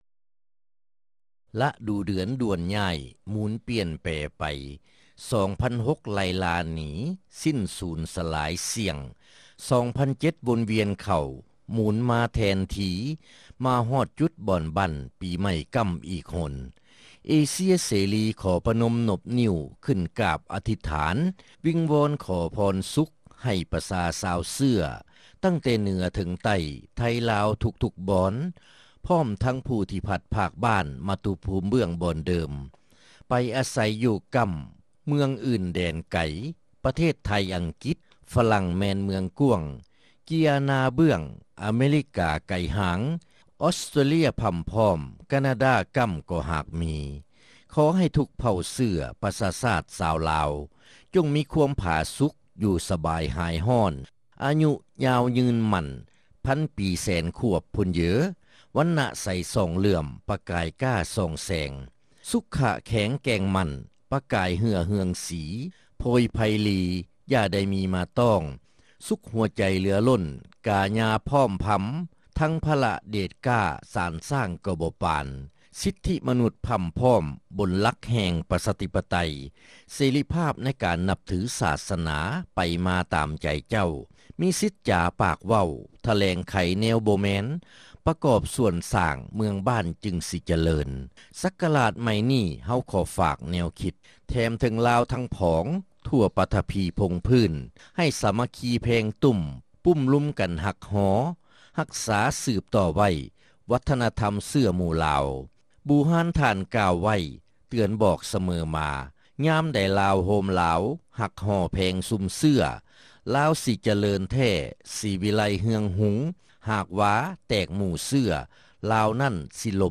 ຣາຍການໜໍລຳ ປະຈຳສັປະດາ ວັນທີ 29 ເດືອນ ທັນວາ ປີ 2006